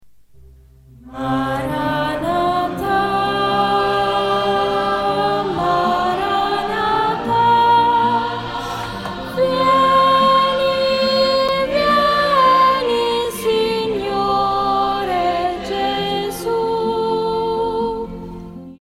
soprano.mp3